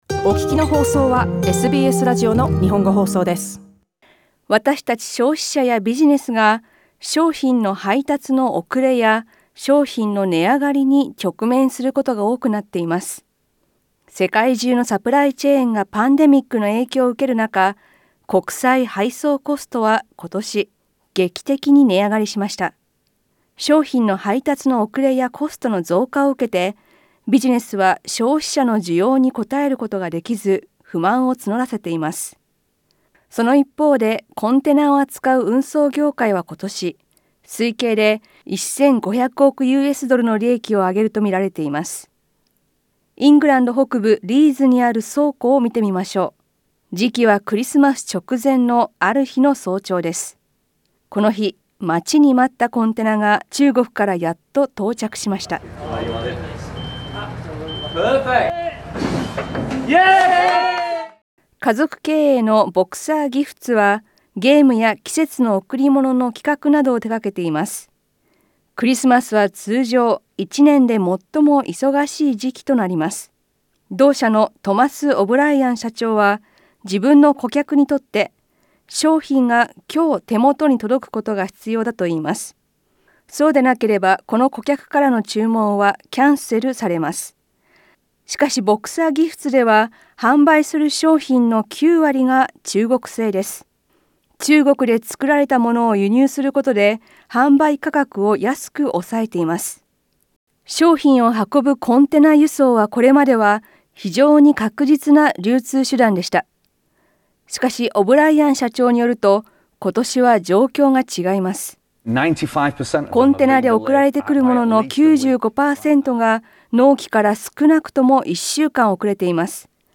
SBSの日本語放送